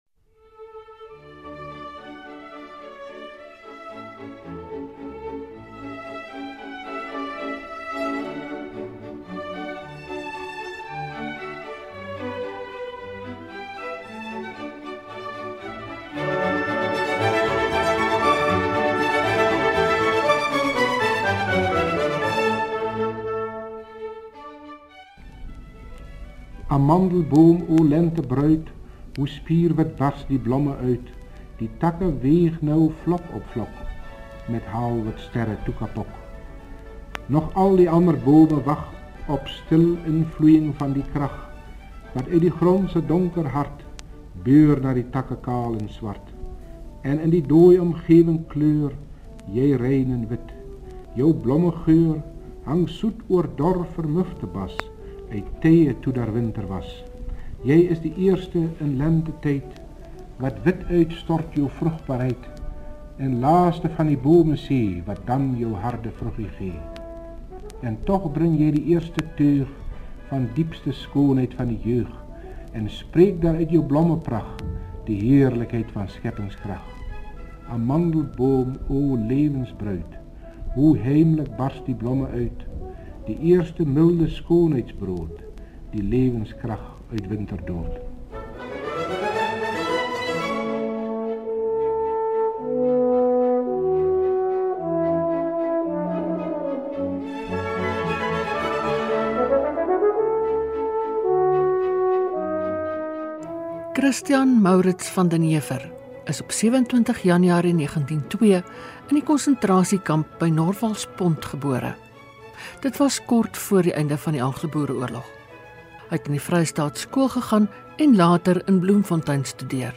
Luister na die dokumentêr